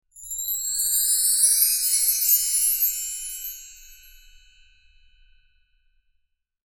Soothing Soft Chimes Transition Sound Effect
Description: Soothing soft chimes transition sound effect. Adds sparkle and smooth flow to your projects.
Perfect for Christmas, celebrations, and ceremonial events, it creates a joyful and enchanting atmosphere.
Soothing-soft-chimes-transition-sound-effect.mp3